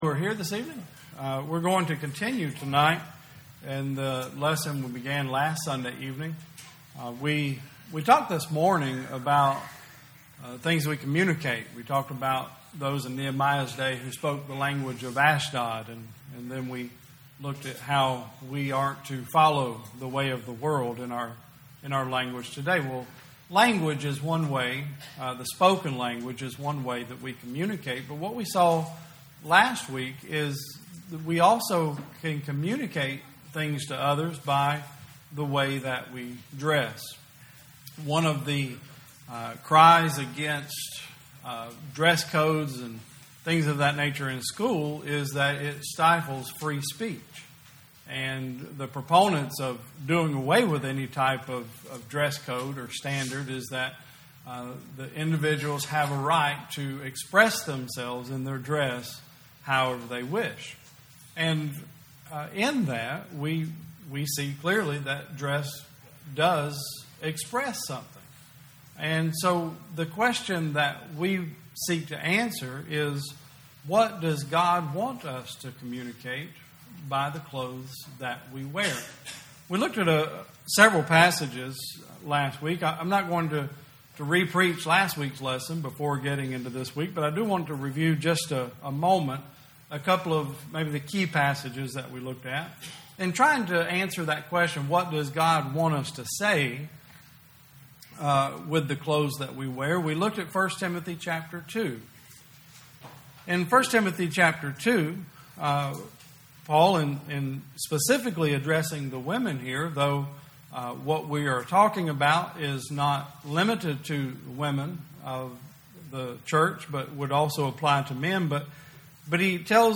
2019 Service Type: Sunday Service Preacher